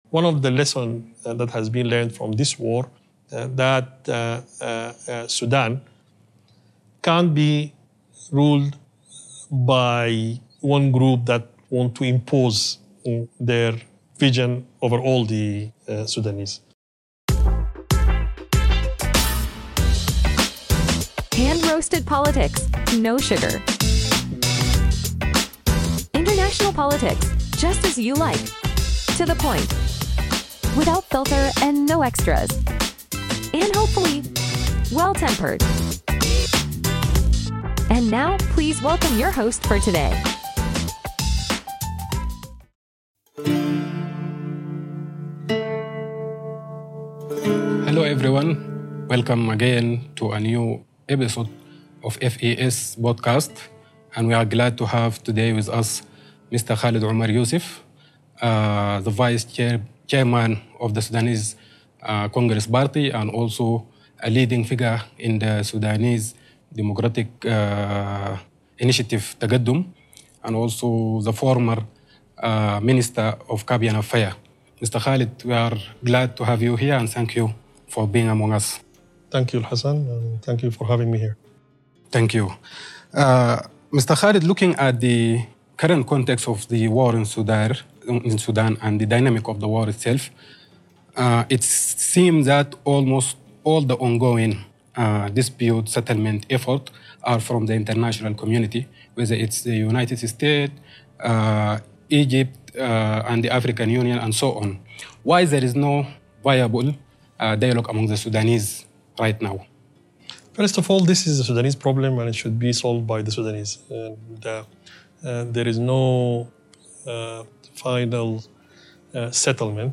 Recorded November 22nd, 2024 in Nairobi. This is episode 3 of Hand Roasted Politics; your dose of international politics, prepared with love by the Friedrich-Ebert-Stiftung.